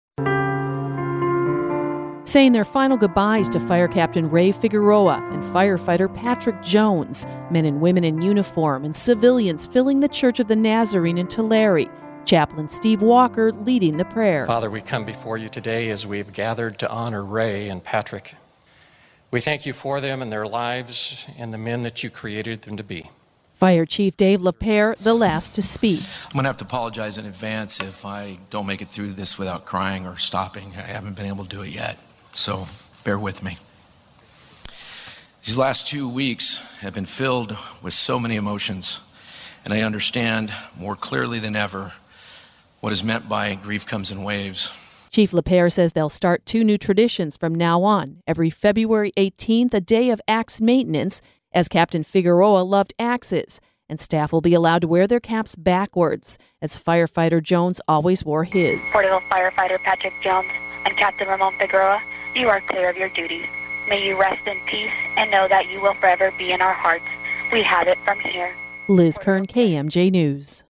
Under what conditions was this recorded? LK-2-FIRE-MEMORIAL.mp3